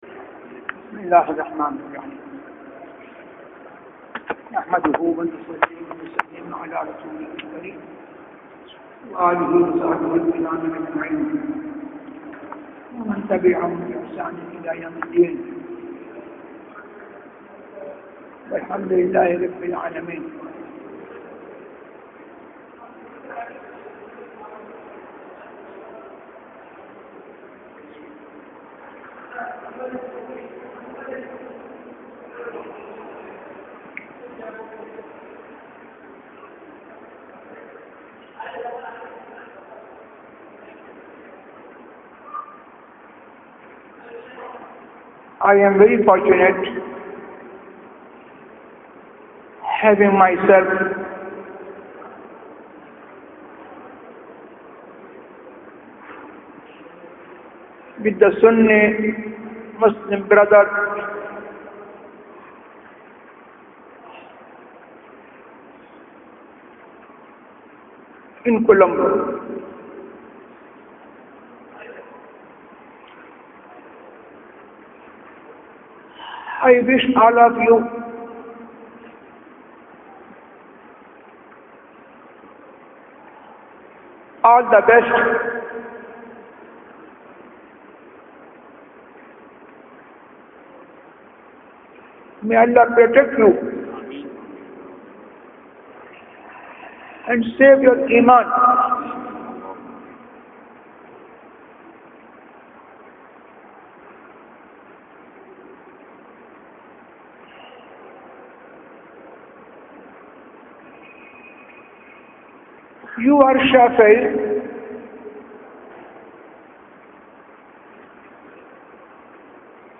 Lecture in Colombo
lecture-in-colombo.mp3